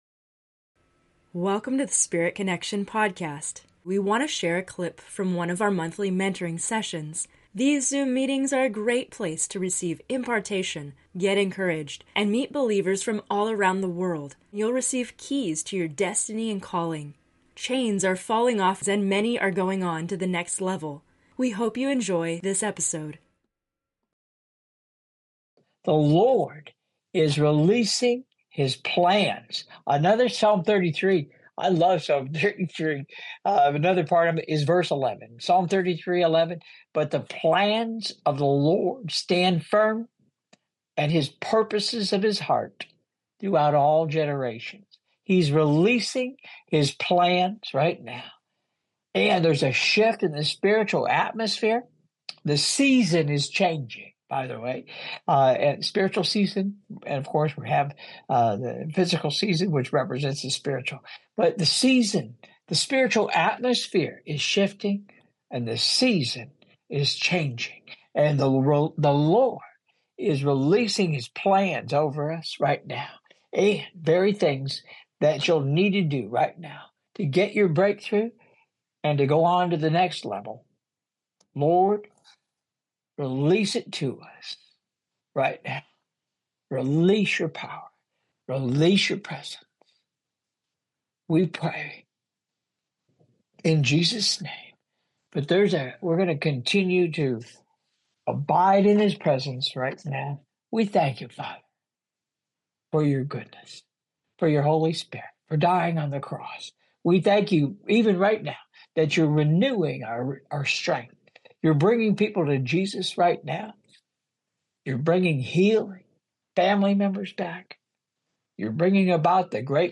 With so much happening around us in the world, prayers of petition are right on time—the Lord is releasing many things!